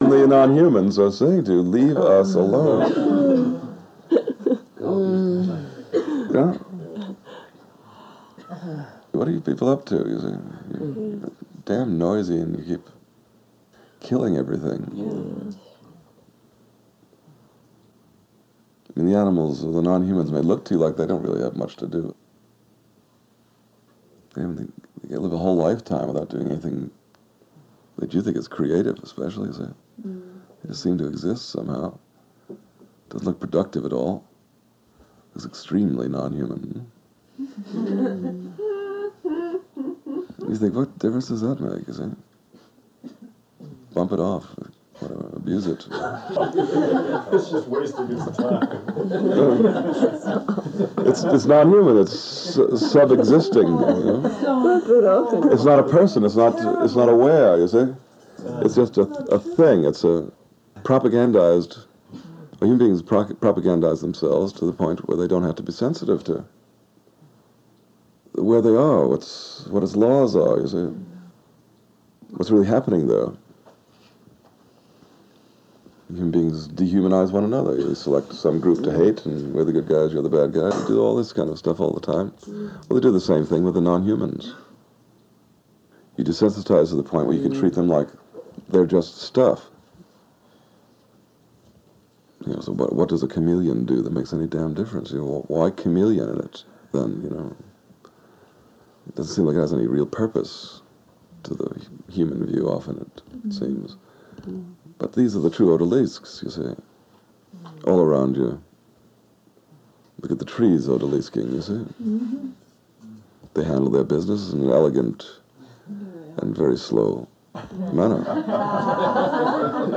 At various times over many years, often later in the evenings, people gathered… maybe in living rooms, in swimming pools, or bedrooms, or on verandahs overlooking the sea.
And once everyone settled, they would listen for hours… to the always mysterious man talking spontaneously about many things, including the naturally-spiritually-contemplative non-human worlds arising across this floating Earth – other worlds which we, also, live within and alongside…
Excerpted from considerably longer recordings, what Adi Da speaks about here is quite absent from the libraries of spiritual literature, whether Christian, Hindu, Buddhist, Muslim, or others.